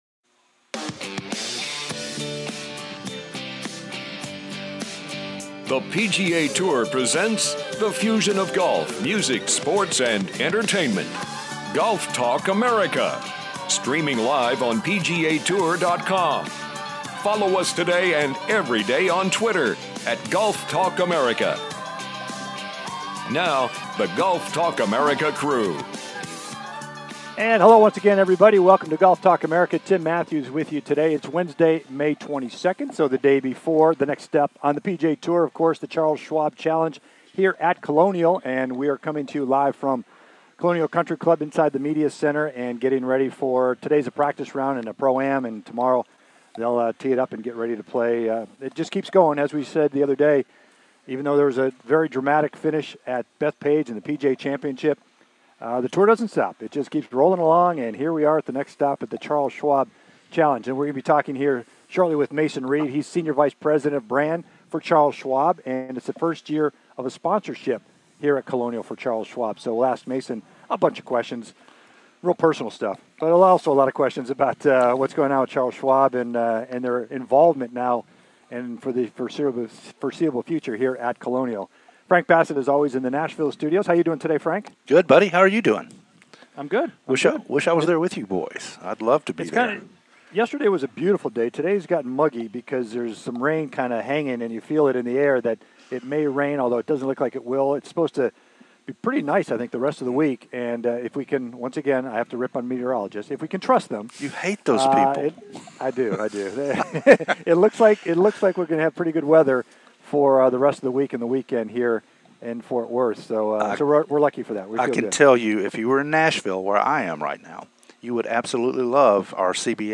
"LIVE" From The Charles Schwab Challenge
with a Special Guest from The Charles Schwab Challenge at Colonial Country Club in Ft. Worth, TX